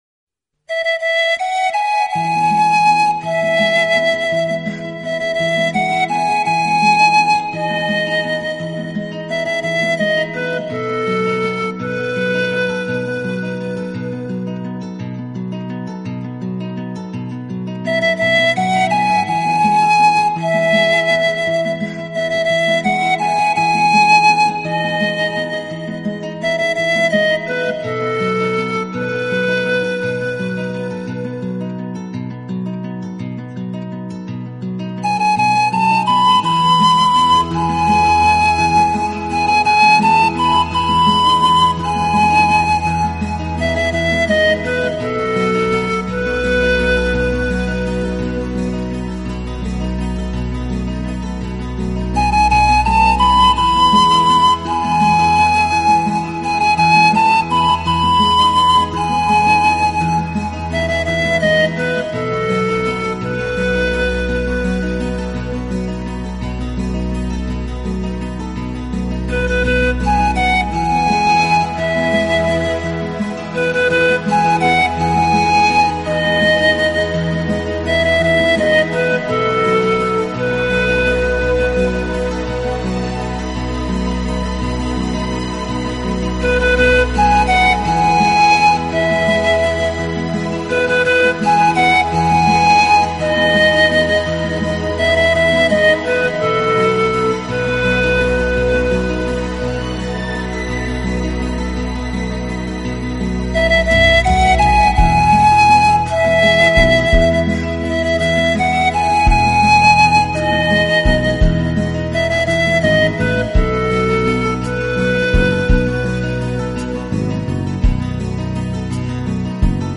这位气质独特的大提琴演奏家，从而诞生了一个全新的音乐品牌——Ocarina（陶笛之歌）。
陶笛晶莹剔透的音色、排箫飘逸独特的风格和淳厚低沉的伴奏构成了这一独特的组合。